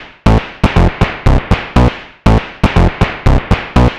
TSNRG2 Bassline 017.wav